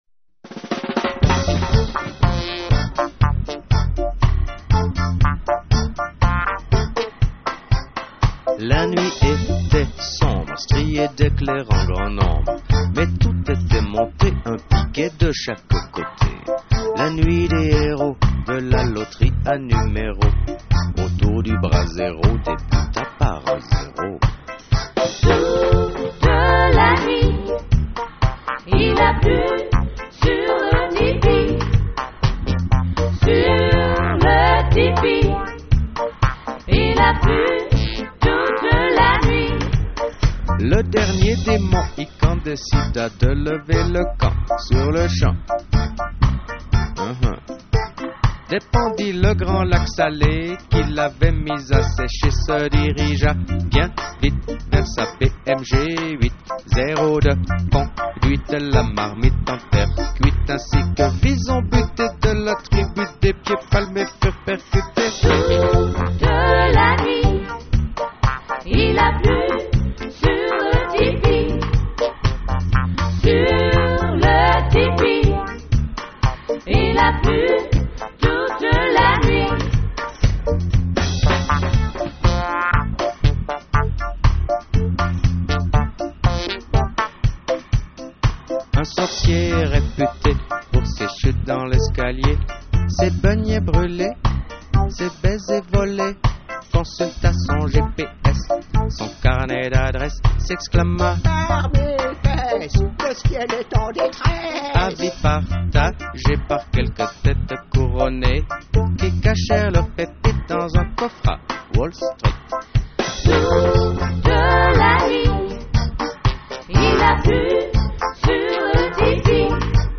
drums, percussions
percussions, congas, berimbau